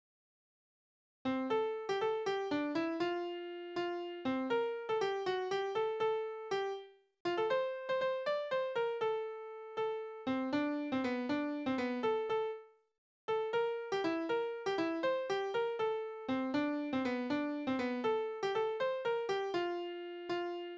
Balladen: Die zwei Schwäne am See
Tonart: F-Dur
Taktart: 6/8
Tonumfang: kleine Dezime
Besetzung: vokal
Anmerkung: Vortragsbezeichnung: Volkslied